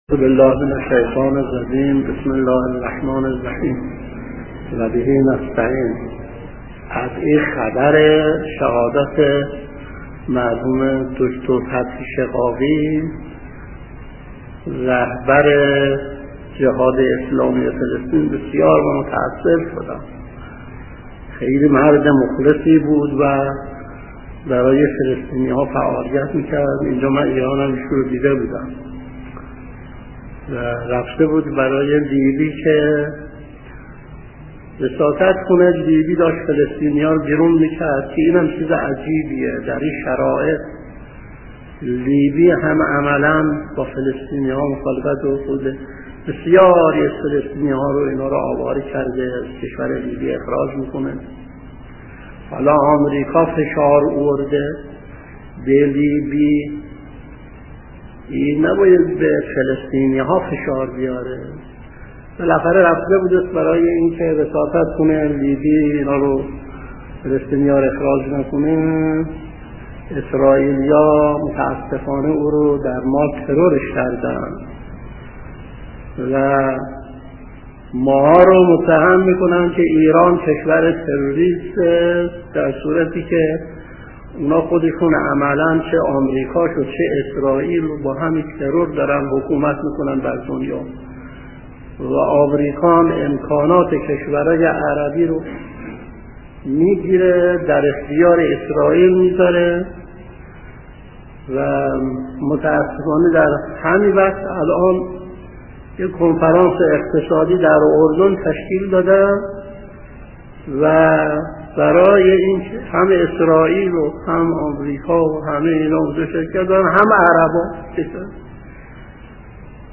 درس 373